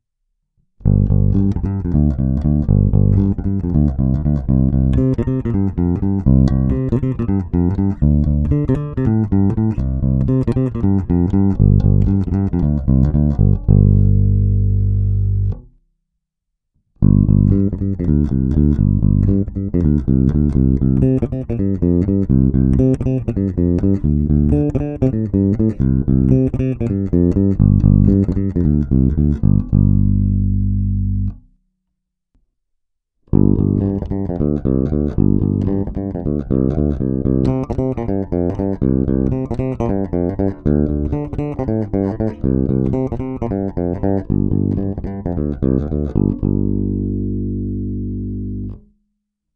Zvuk má modernější projev, je pěkně tučný, se sametovými nižšími středy, ovšem taky je nepatrně zastřený díky použitým humbuckerům.
Není-li uvedeno jinak, následující nahrávky jsou provedeny rovnou do zvukové karty, s plně otevřenou tónovou clonou a na korekcích jsem trochu přidal jak basy, tak výšky.